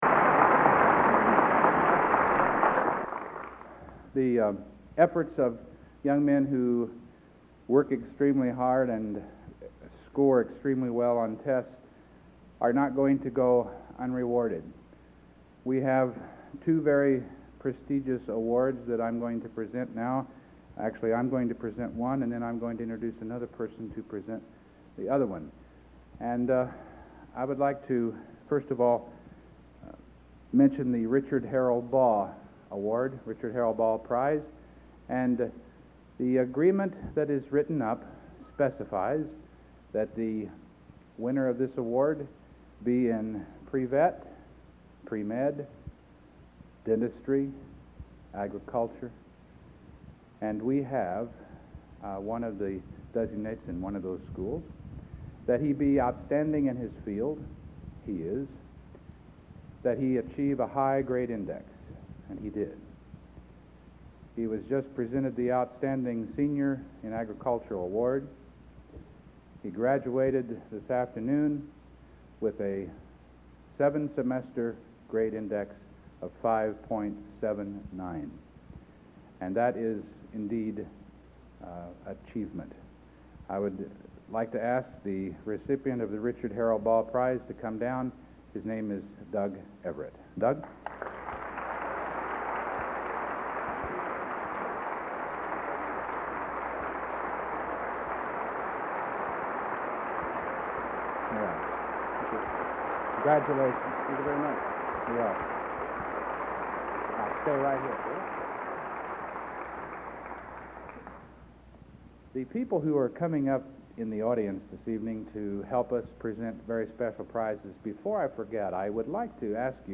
Location: West Lafayette, Indiana
Genre: | Type: Director intros, emceeing |End of Season